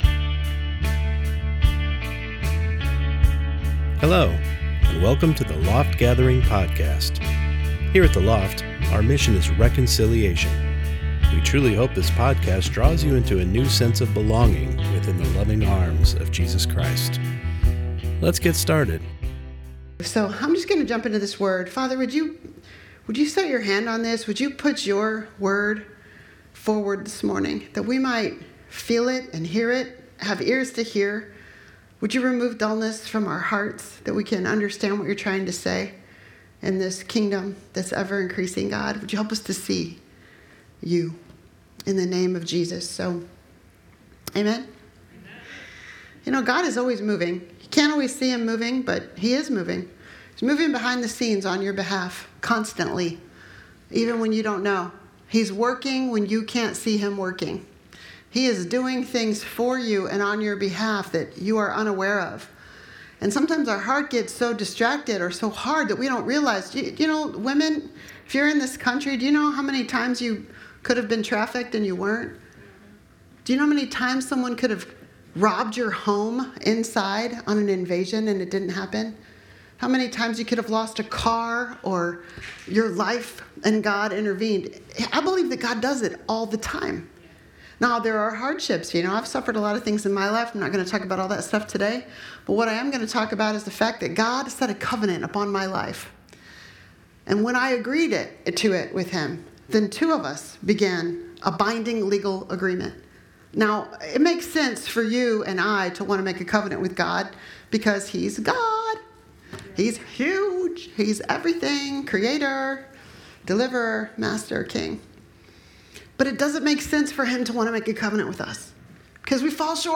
Sunday Morning Service Service